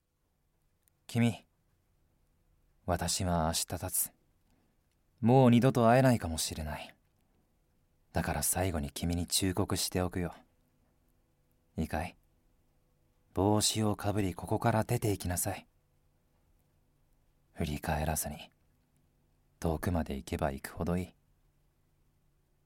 セリフA